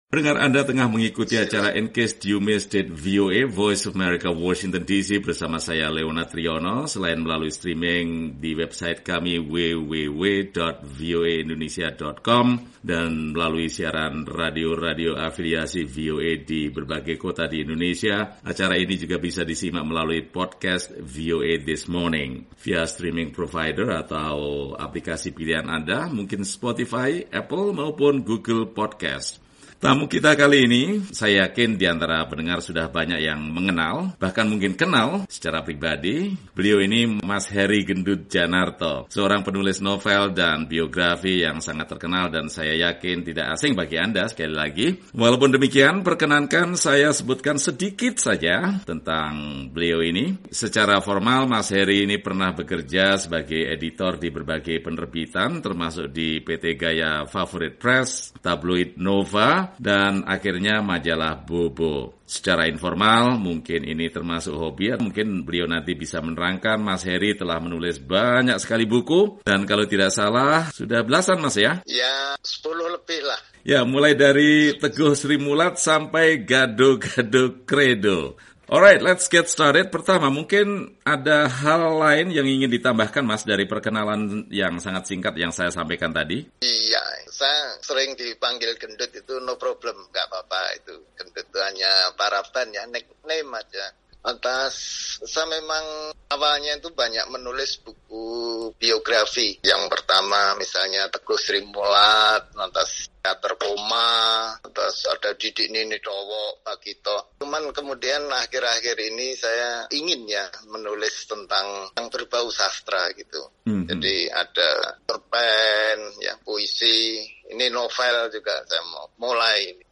Bincang-bincang